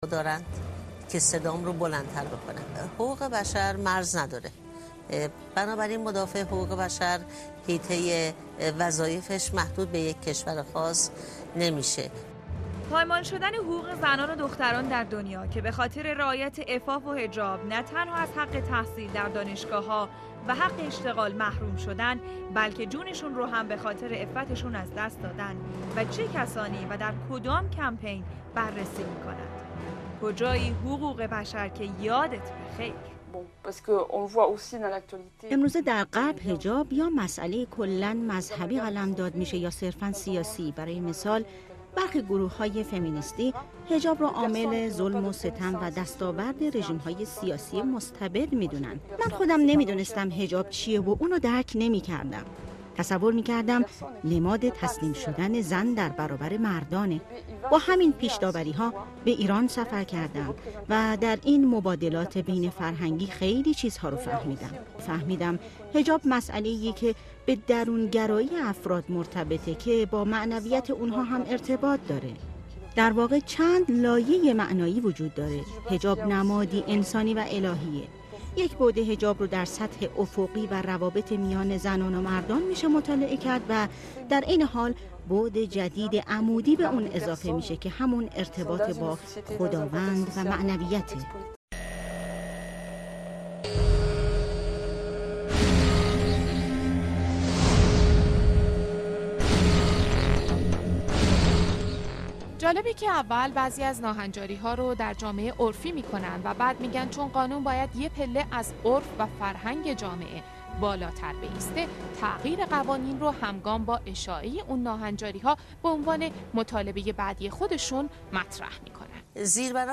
مستند جذاب و دیدنی فمینیسم و جنگ علیه خانواده که در این قسمت به موضوع کمپین یک میلیون امضا پرداخته می‌شود که چگونه القاء می‌شود بر ذهن جامعه عادی سازی روابط دختر و پسر عادی سازی طلاق و …